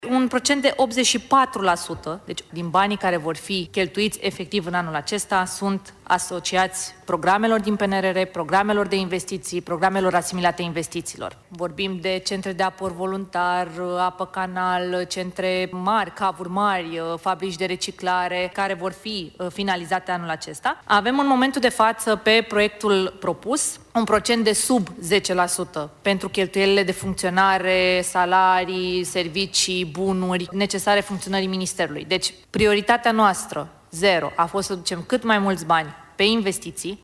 84% din bugetul Ministerului Mediului merge anul acesta pe investiții, a explicat ministra Diana Buzoianu la prezentarea bugetului în comisiile de specialitate din Parlament.